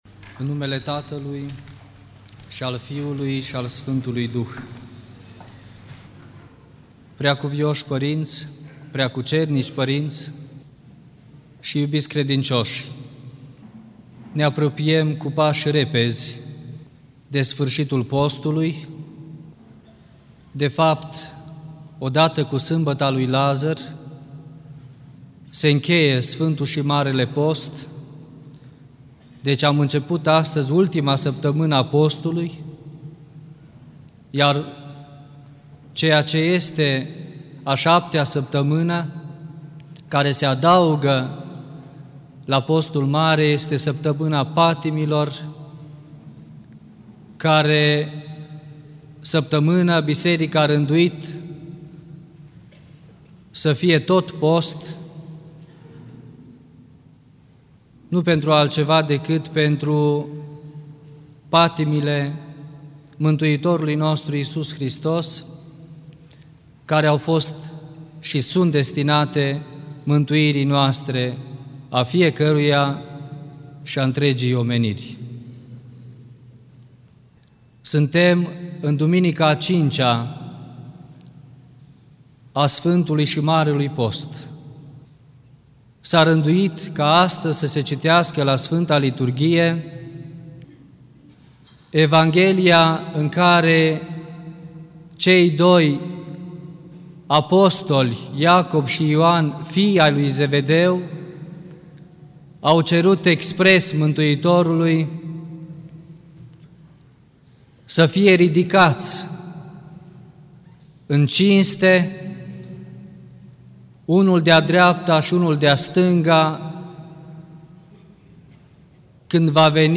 Predică la Duminica a 5-a din Post
Cuvinte de învățătură Predică la Duminica a 5-a din Post